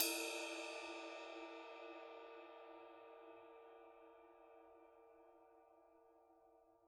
susCymb1-hitstick_f_rr1.wav